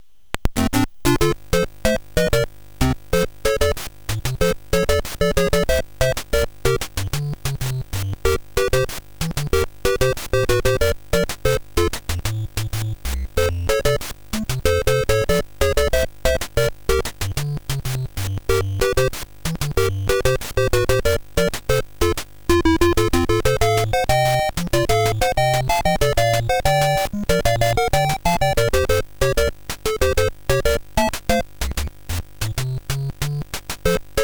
Но качество картинки и звука отвратительное, для сравнения картинки и аудио-файлы.